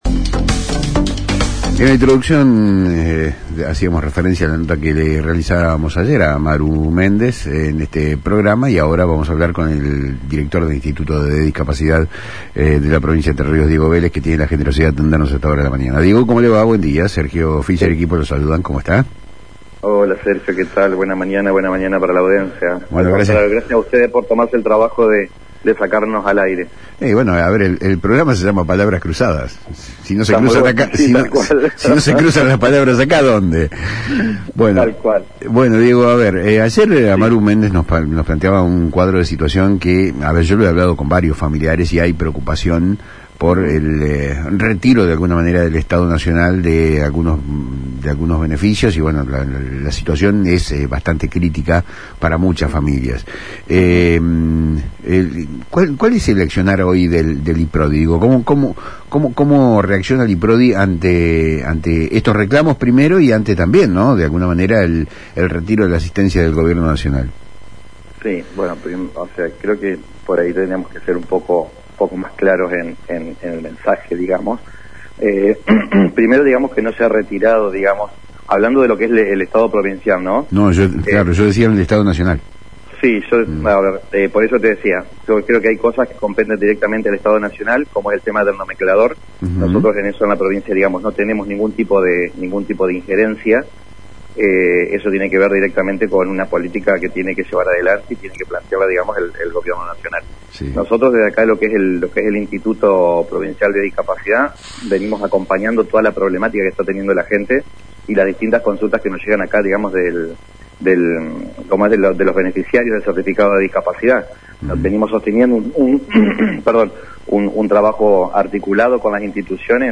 En una entrevista exclusiva con el programa «Palabras Cruzadas»